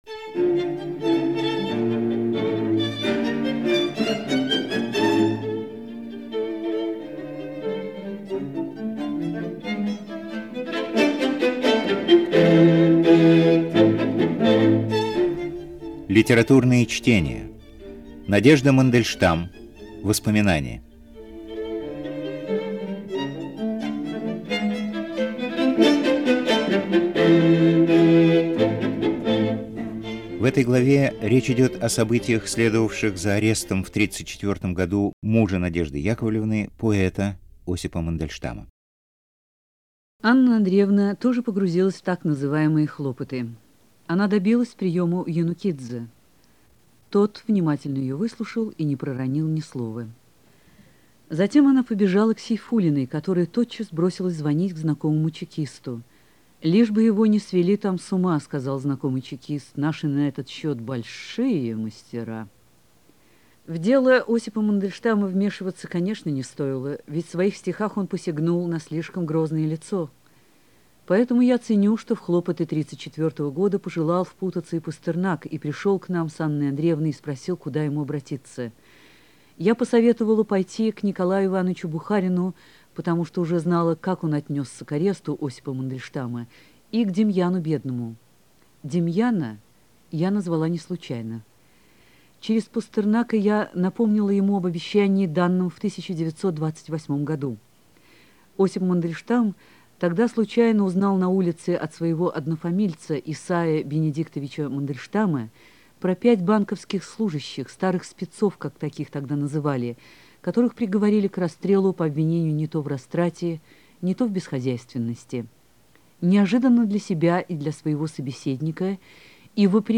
Още от началото на 80-те години познавах от късовълновото радио аудио-спектаклите на руската служба на Voice of America по тези книги.